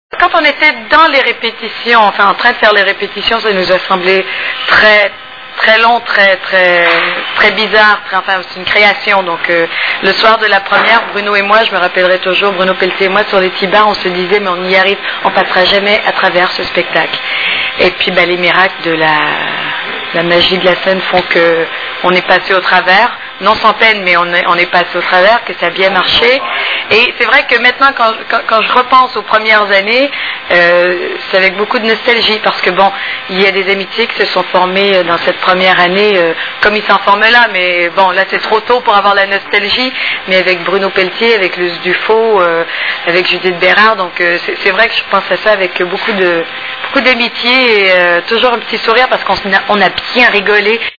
STARMANIA...Interview de Jasmine Roy !!!
( Casino de Paris, Hall d’entrée, 06/02/2000 )